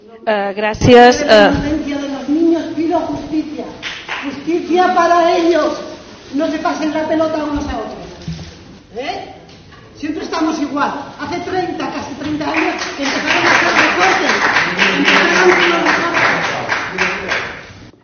Una mujer irrumpe en el Parlamento Balear y exige justicia para los niños «violados»
La mujer hizo su aparición durante la intervención de la consejera de Asuntos Sociales, Fina Santiago, que fue interrumpida al grito de «justicia».